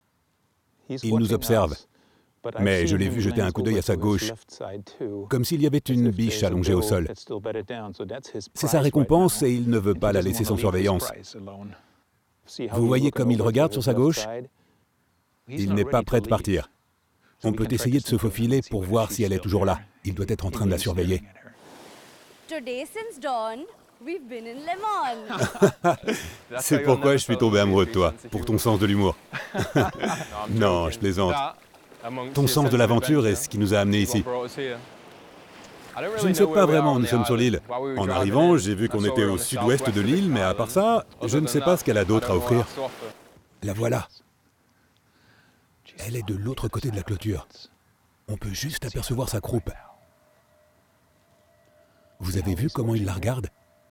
Voice-Over
30 - 50 ans - Basse